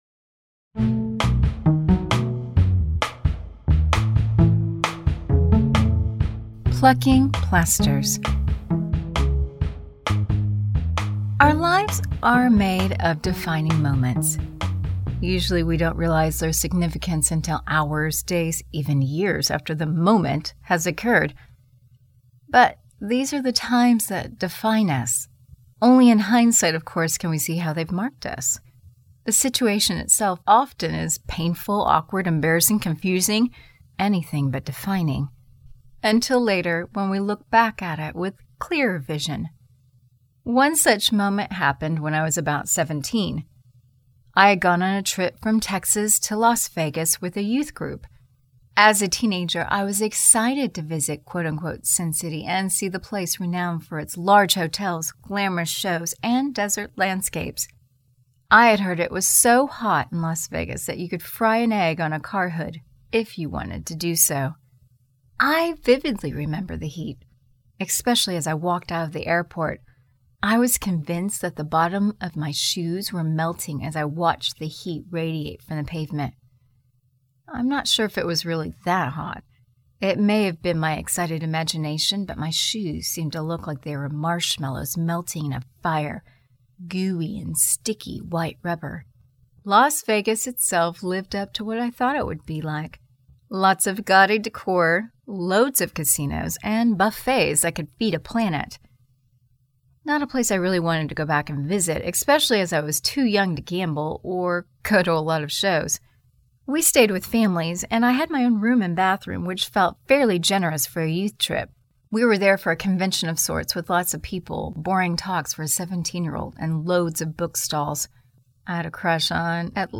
September 14, 2016 Drama, Series, Storytelling
Music: Featuring a musical sting